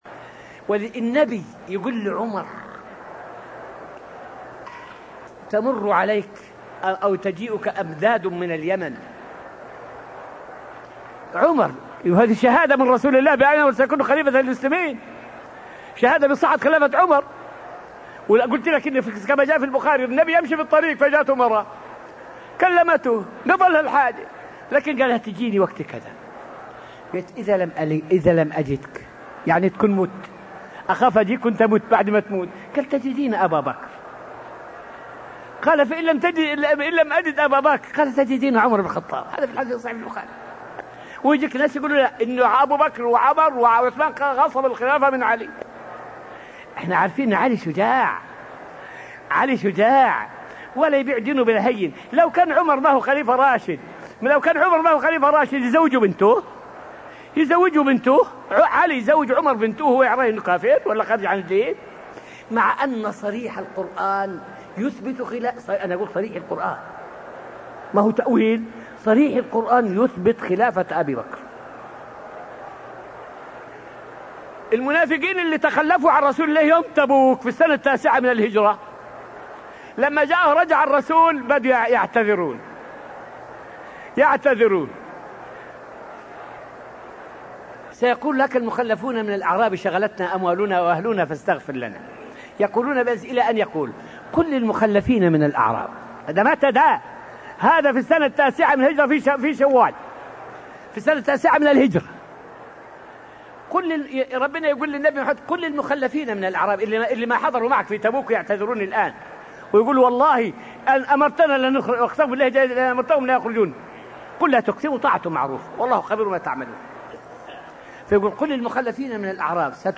فائدة من الدرس الثاني والعشرون من دروس تفسير سورة البقرة والتي ألقيت في المسجد النبوي الشريف حول أدلة القرآن والسنة على خلافة أبا بكر وعمر.